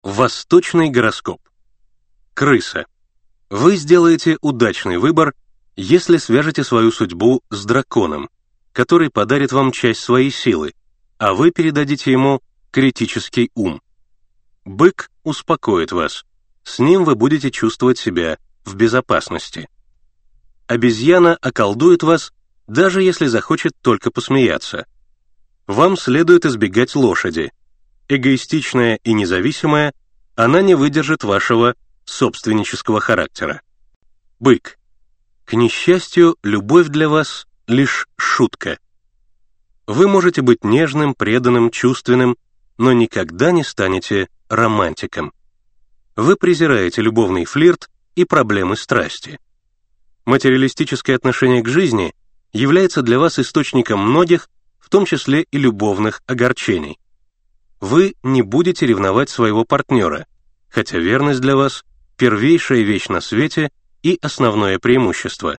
Аудиокнига Зодиакальный гороскоп | Библиотека аудиокниг